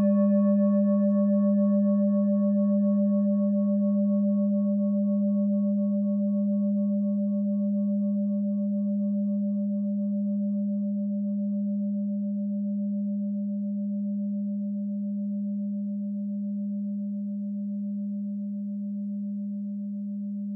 Klangschalen-Typ: Bengalen
Klangschale Nr.2
Gewicht = 1120g
Durchmesser = 19,3cm
(Aufgenommen mit dem Filzklöppel/Gummischlegel)
klangschale-set-1-2.wav